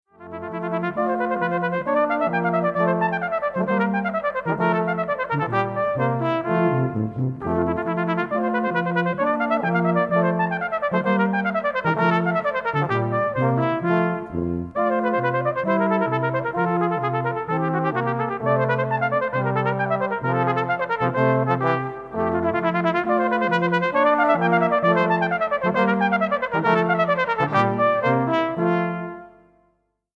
40 - Copier - Ensemble de cuivres Epsilon